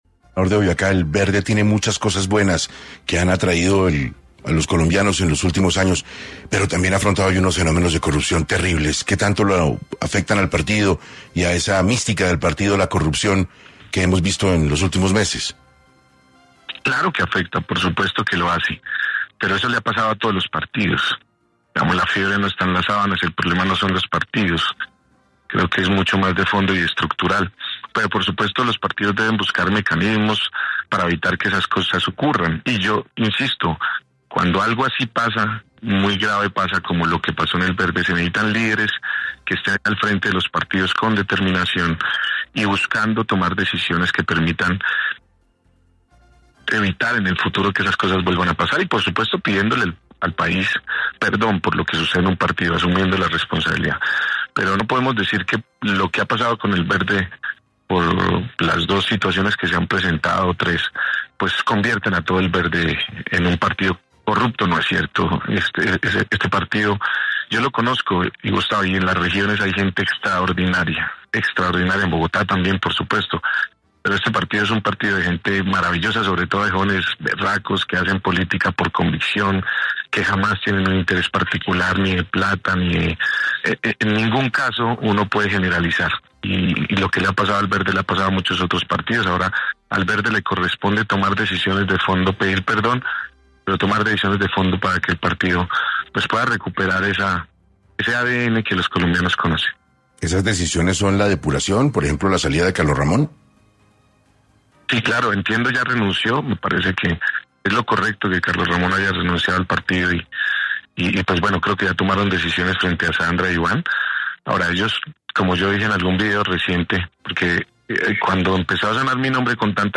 La noticia fue confirmada por Carlos Amaya en 6AM.